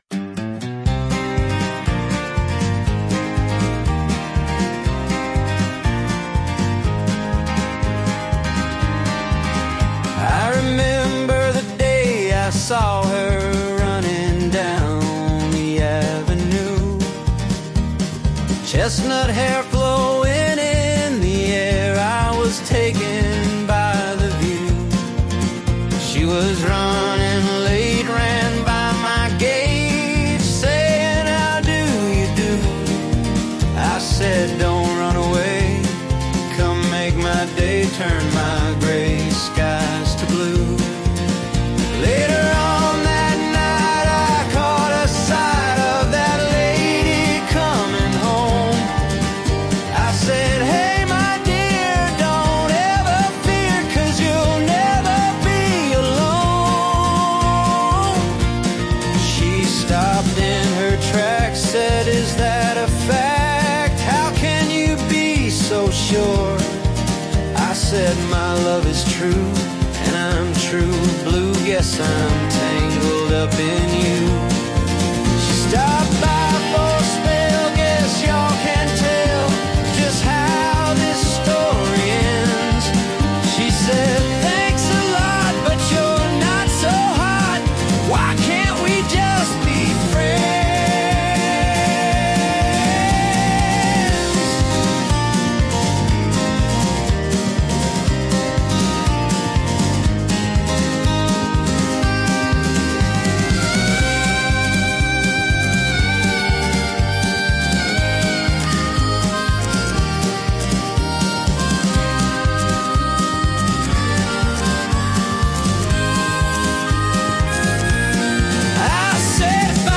I put some vocals and music to make it a fun song